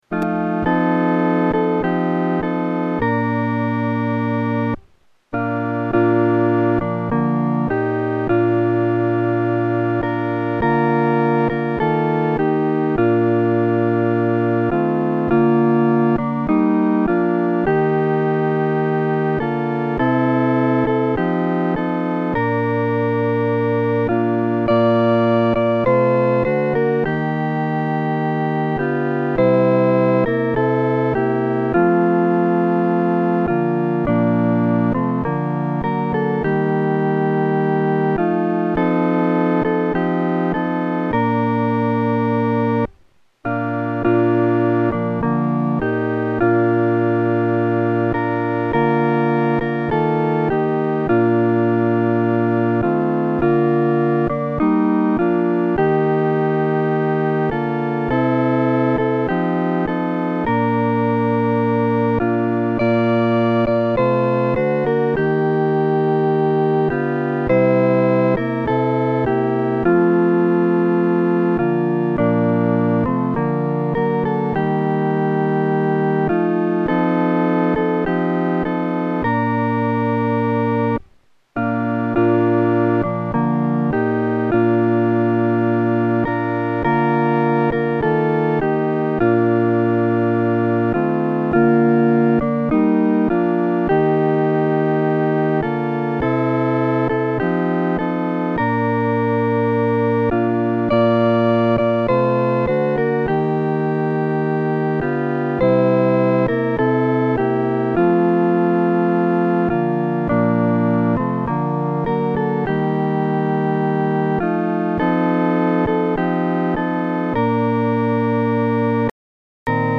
伴奏
四声